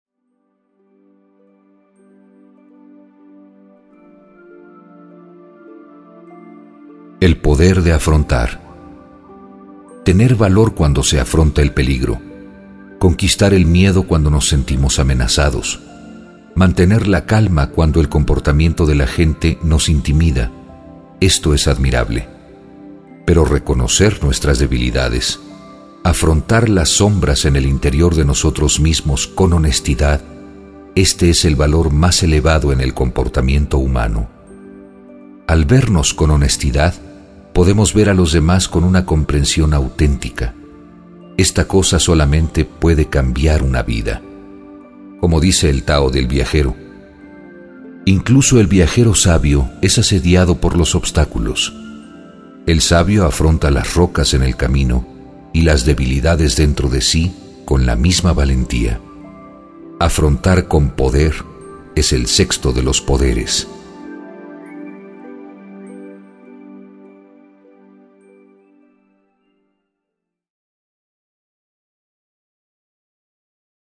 Genre Meditaciones Guiadas